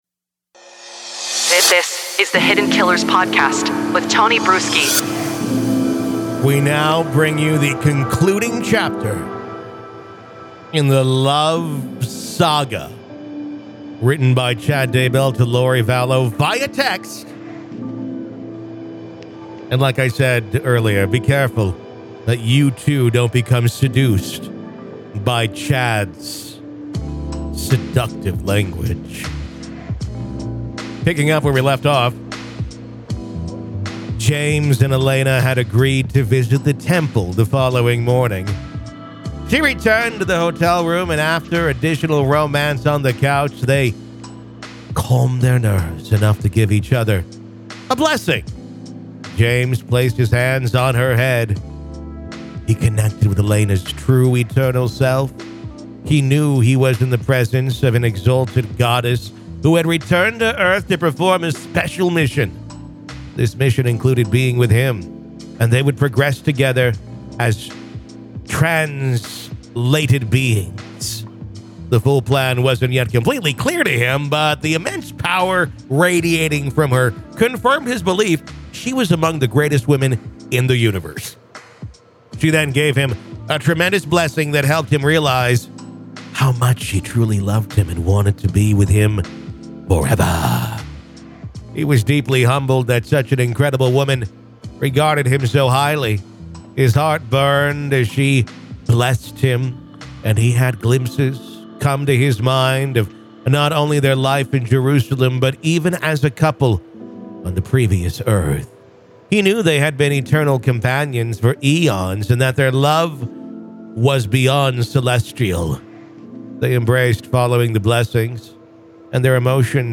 In a captivating audio performance, the intimate love letters penned by Chad Daybell to Lori Vallow are dramatically enacted for listeners.
The reading brings to life the emotions, nuances, and tone embedded in the words written by Daybell.